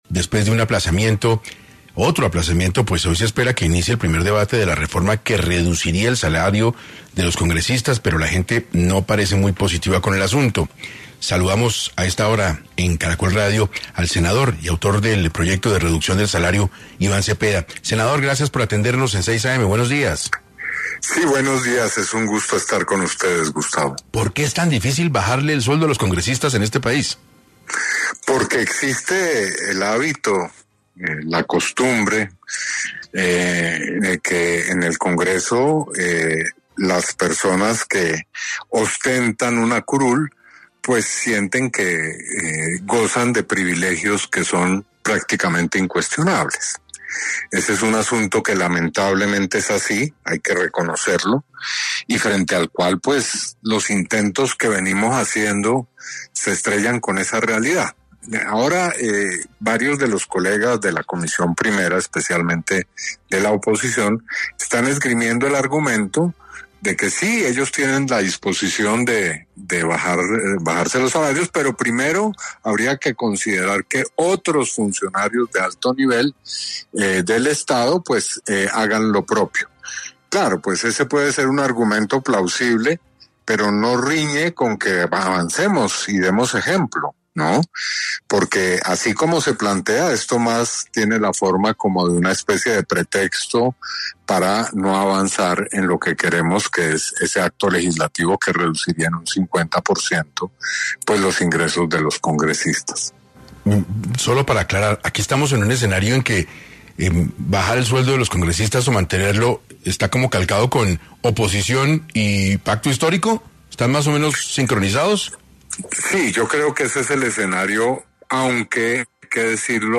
El senador Iván Cepeda habló sobre las posibilidades que tiene este proyecto de ley, en un contexto político difícil para las reformas del gobierno
En entrevista en 6AM Caracol Radio, Cepeda reconoció las dificultades para avanzar con la propuesta, pero aseguró que, a pesar de los obstáculos, la medida sigue siendo esencial para dar ejemplo y responder a la crisis fiscal que enfrenta el país.